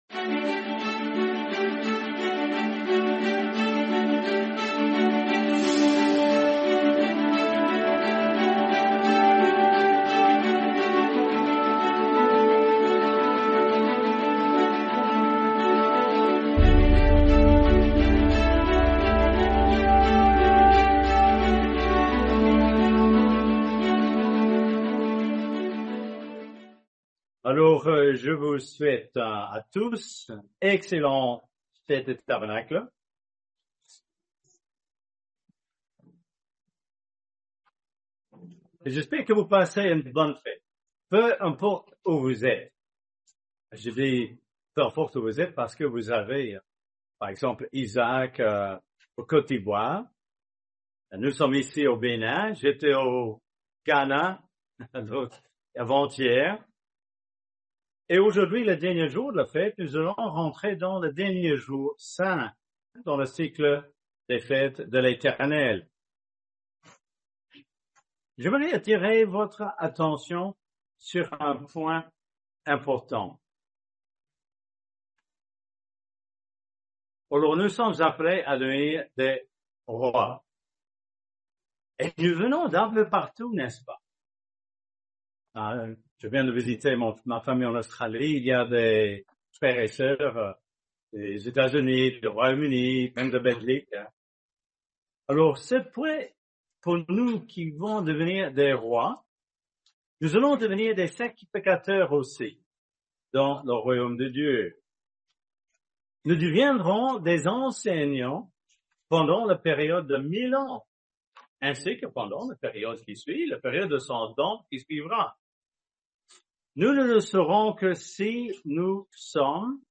Fête des Tabernacles – 6e jour